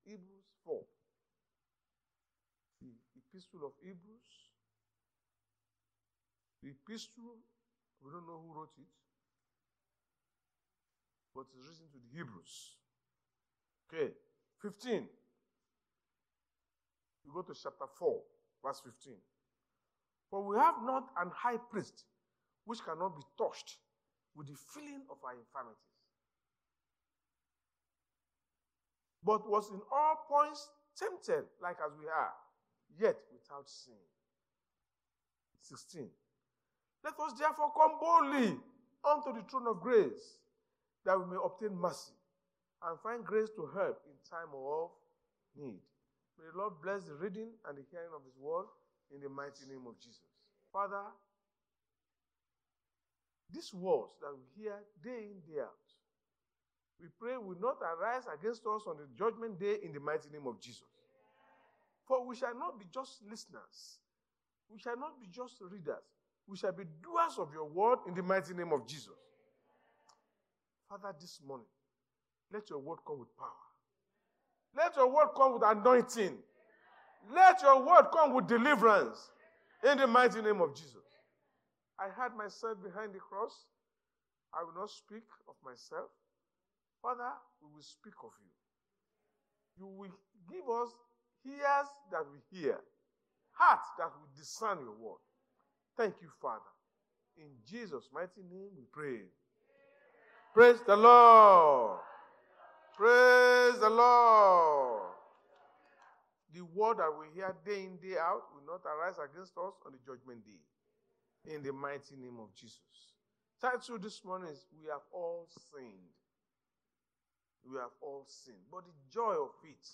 Sunday Sermon-We Have All Sinned
Service Type: Sunday Church Service